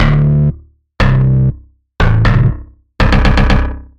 蹦蹦床低音
描述：重低音
Tag: 120 bpm Electronic Loops Bass Loops 689.15 KB wav Key : Unknown